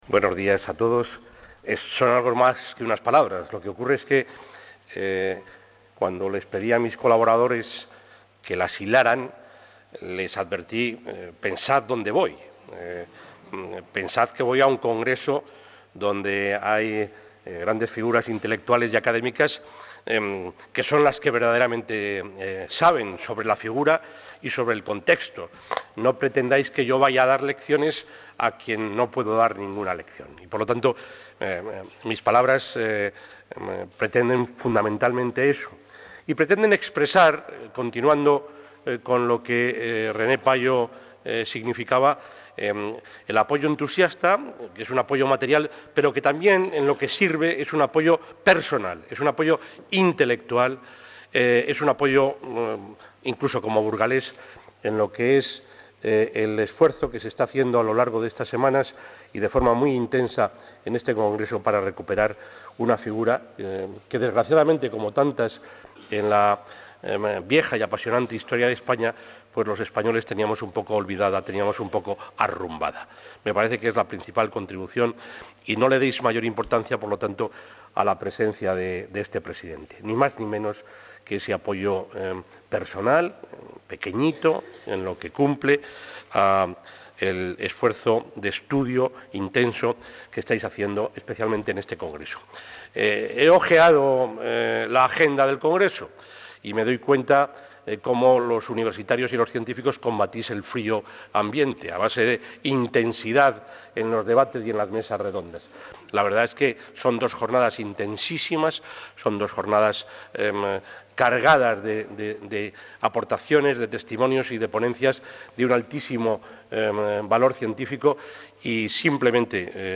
Audio presidente de la Junta de Castilla y León.
El presidente de la Junta de Castilla y León ha participado en el Congreso Internacional ‘La memoria de un hombre. Francisco de Enzinas en el V Centenario de la Reforma protestante», que se ha celebrado esta mañana en la Universidad de Burgos